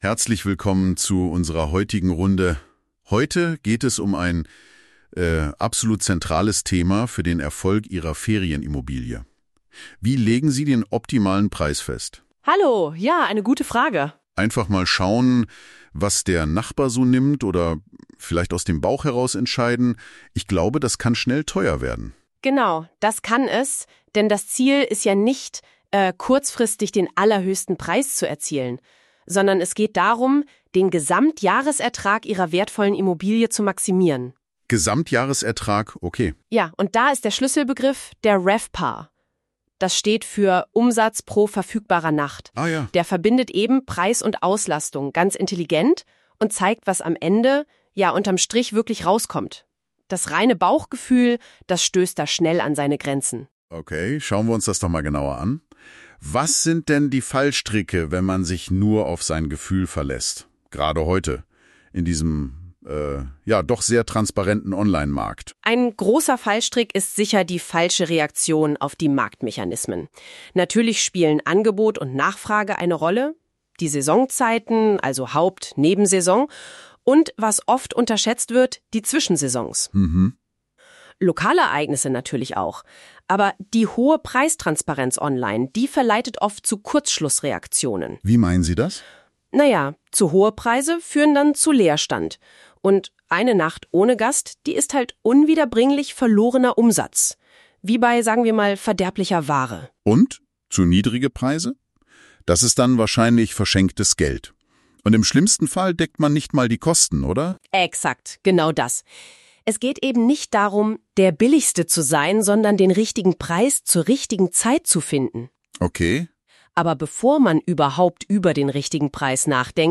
Podcast-Duo zur Ferienhaus-Verwaltung: Experten-Tipps für Vermieter, mehr Erfolg mit Ferienwohnungen.